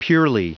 Prononciation du mot purely en anglais (fichier audio)